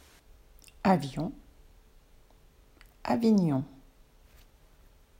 7. Avion: Flugzeug (avjõ)
Richtig ausgesprochen ist das I ganz kurz, eigentlich mehr ein J.